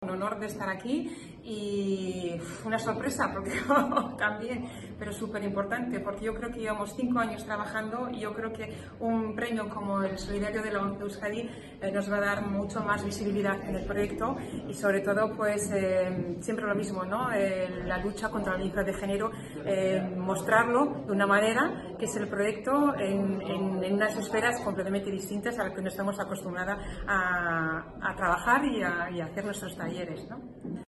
El auditorio Mitxelena, del Bizkaia Aretoa en Bilbao, sirvió de escenario para que la Organización agradeciera y homenajeara a toda la sociedad vasca por la solidaria actitud con la que día a día le apoya a través de la compra de sus productos de juego responsable.